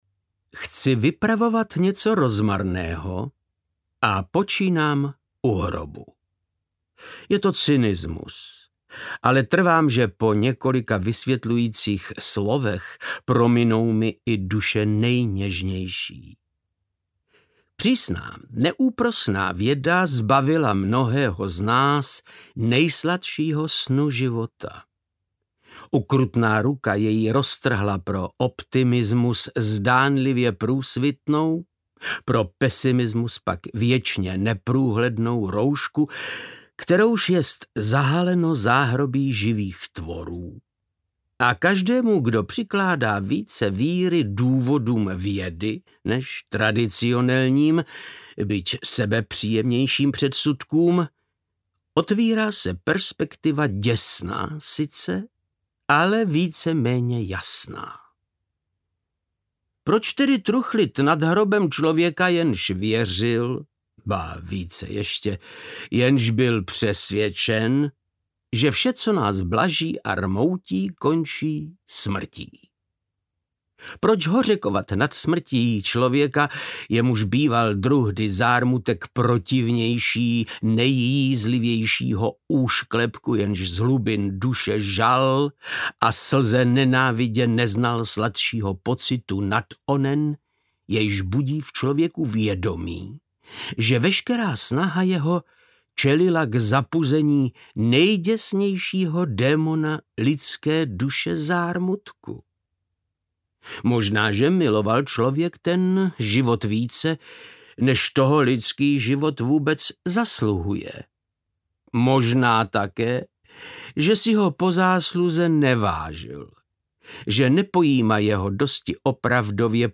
Newtonův mozek audiokniha
Ukázka z knihy
• InterpretVáclav Knop